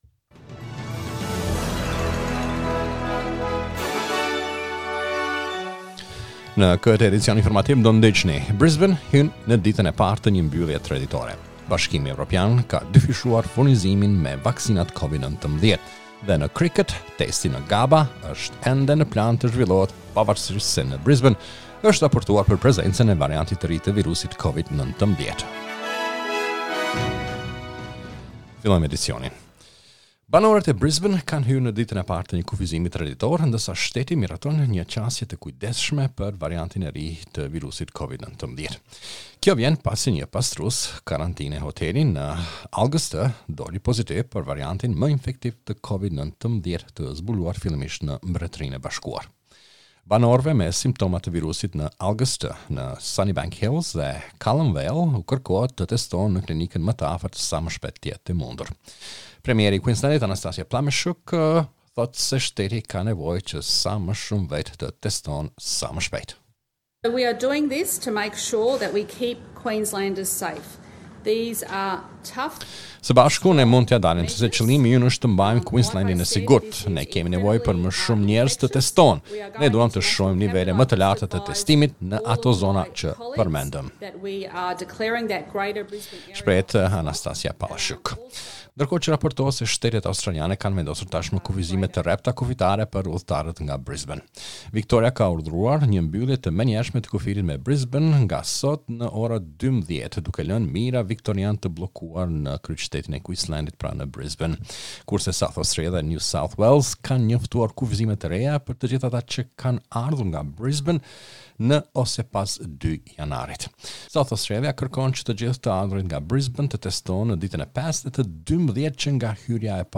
SBS News Bulletin in Albanian - 09 January 2021